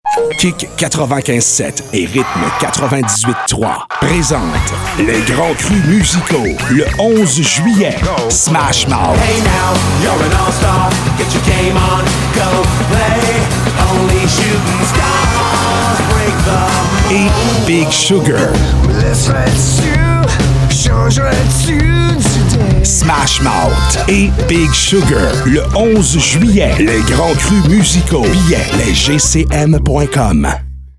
PROMO RADIO SMASH MOUTH & BIG SUGAR